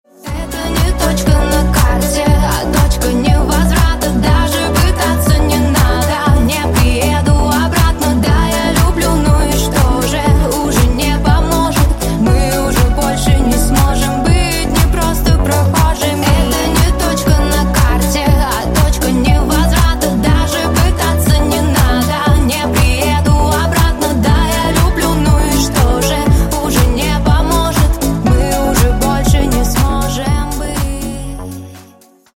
Поп Рингтоны